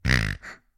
Звуки игрушек для собак
Звук игрушечной свиньи